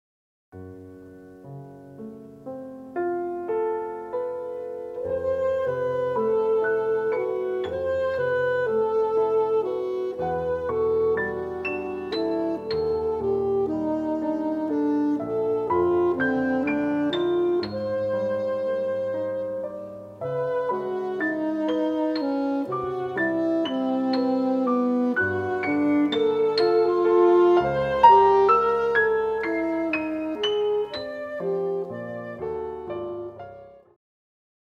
alto saxophone & piano